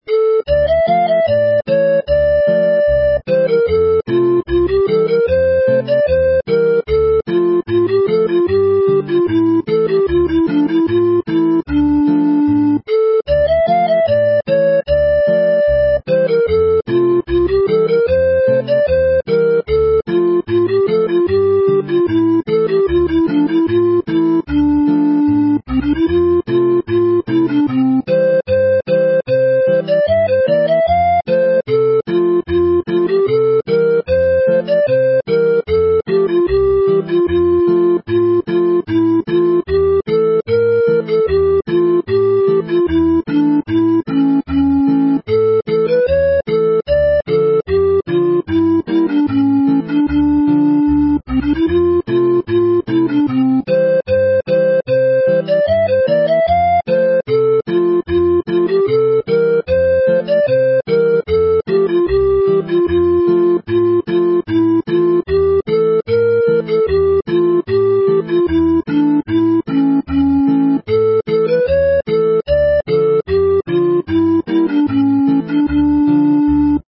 it is based on the key of D minor, with a cheering change to D major to close.